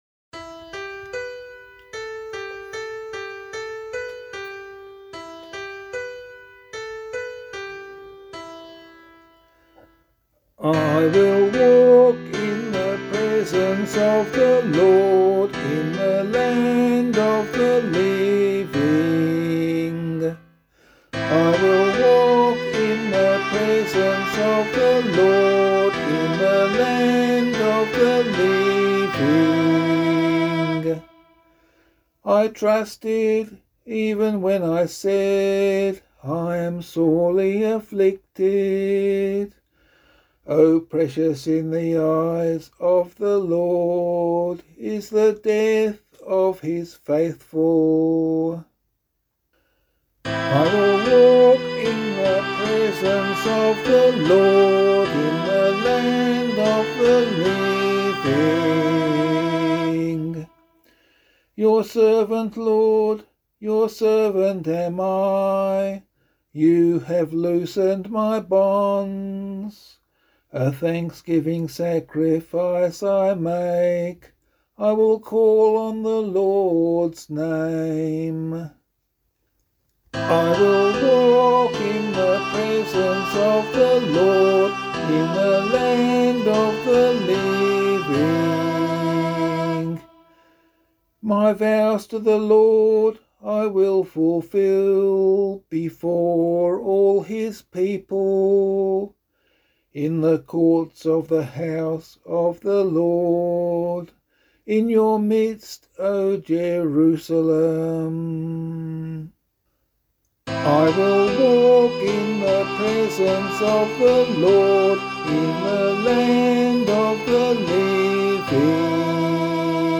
014 Lent 2 Psalm B [LiturgyShare 4 - Oz] - vocal.mp3